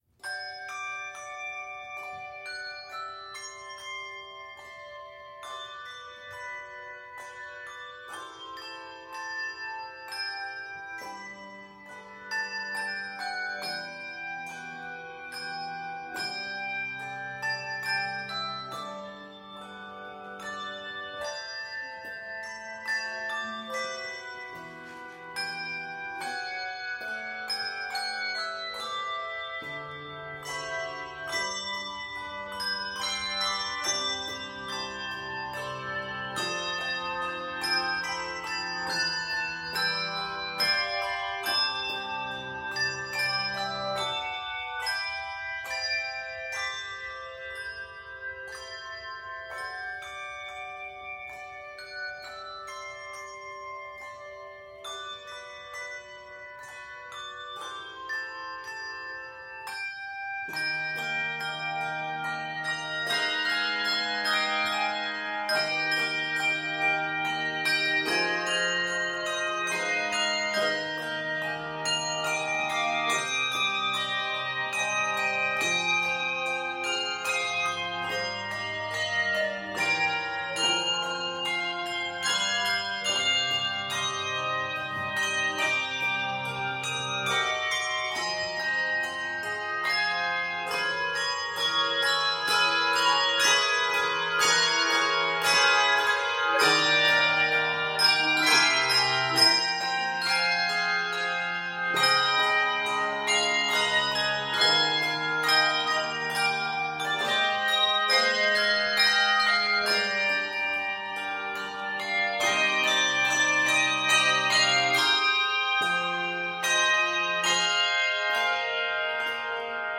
compelling, reflective ballad for 3 to 5 octave handbells